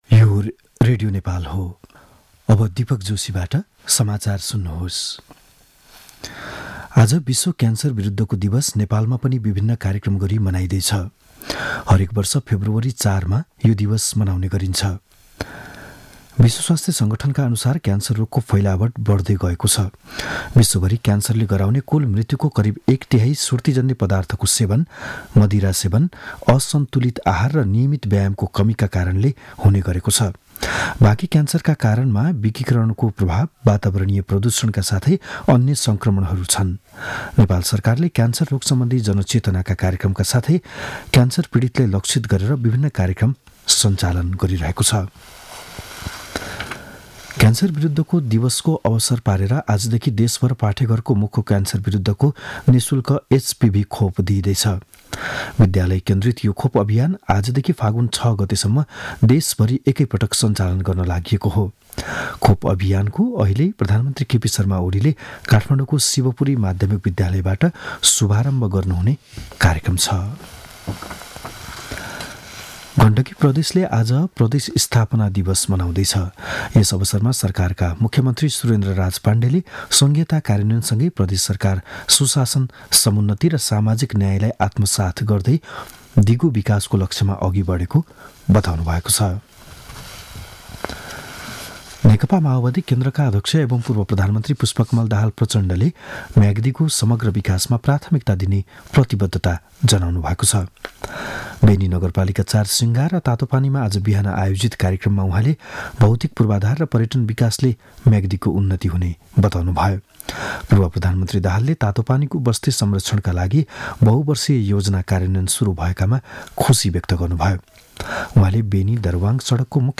बिहान ११ बजेको नेपाली समाचार : २३ माघ , २०८१
11-am-news.mp3